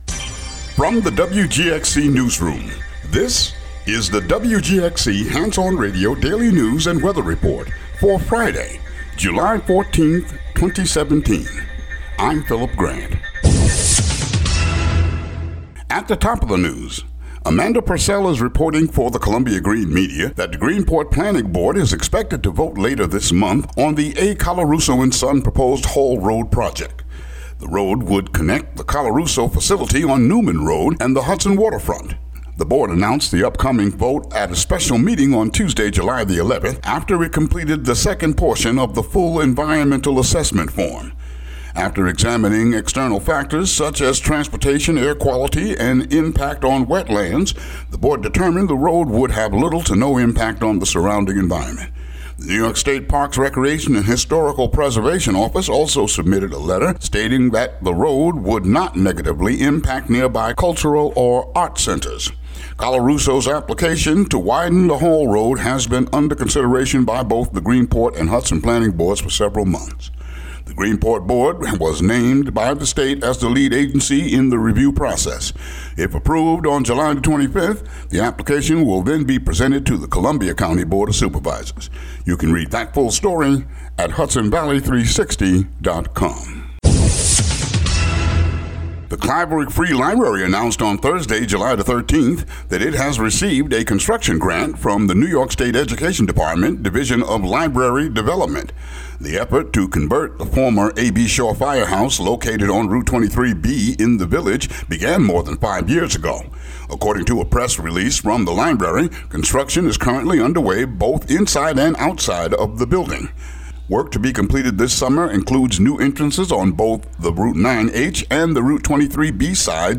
news